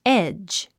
発音
édʒ　エェッジ